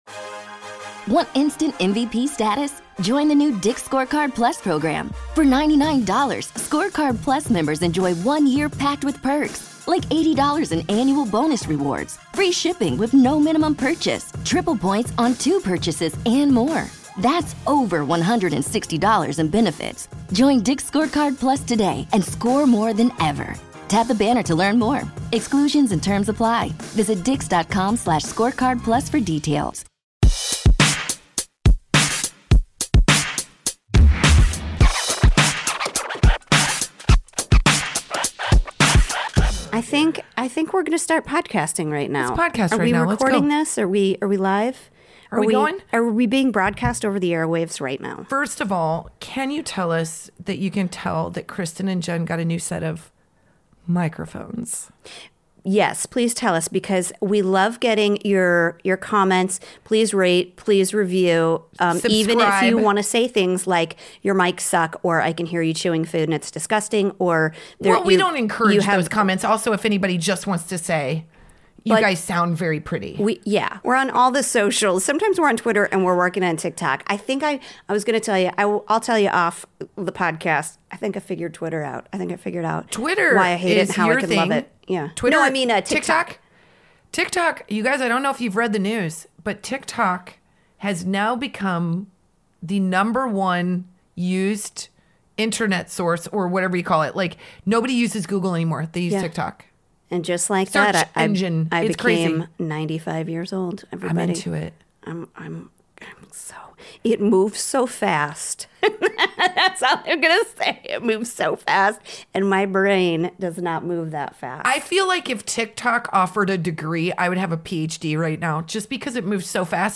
This hilarious comedy podcast about motherhood is for moms by moms talking all about being a mom.
Female comedy duo